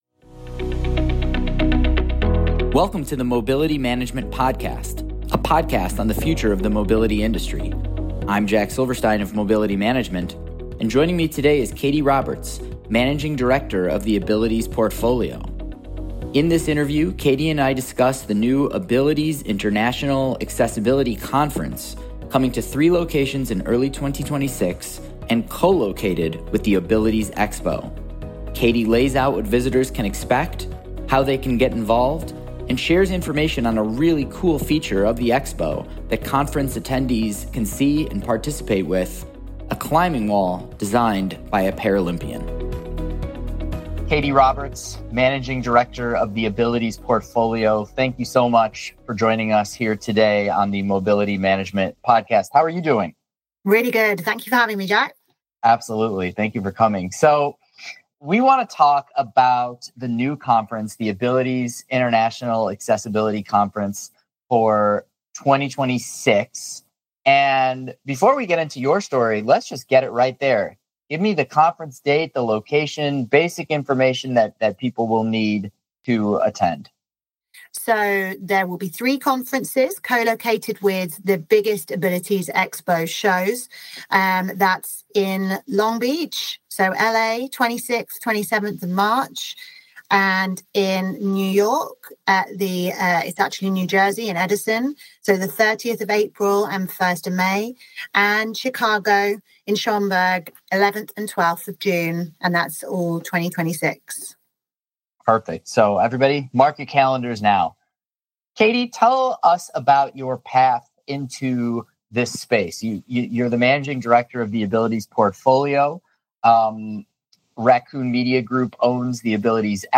Mobility Management Podcast The Abilities Expo is growing! Interview